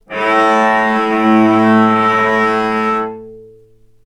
healing-soundscapes/Sound Banks/HSS_OP_Pack/Strings/cello/sul-ponticello/vc_sp-A2-ff.AIF at 61d9fc336c23f962a4879a825ef13e8dd23a4d25
vc_sp-A2-ff.AIF